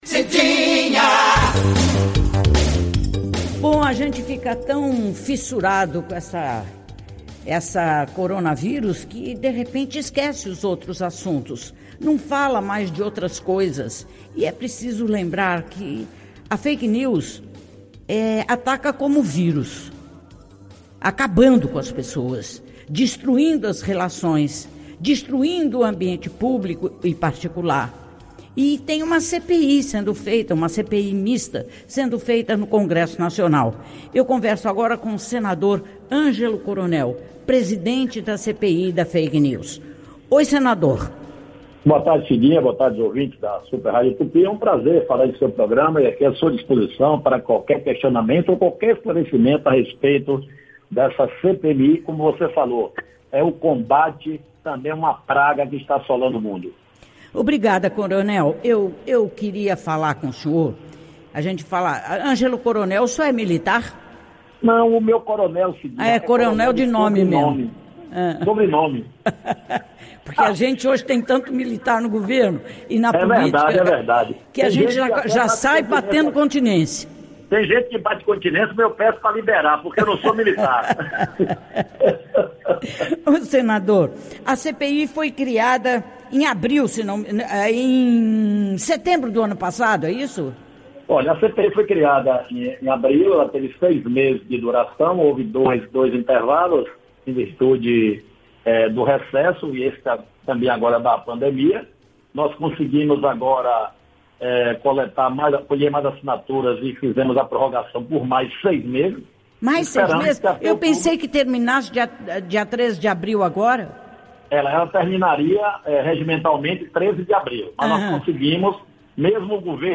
O senador Angelo Coronel (PSD), presidente da CPMI da Fakes News participou, nesta quarta-feira (08), do Programa Cidinha Livre, da Super Rádio Tupi.
Durante a entrevista, o parlamentar falou sobre a criação da CPMI, do trabalho realizado para combater notícias falsas e sobre a prorrogação da comissão.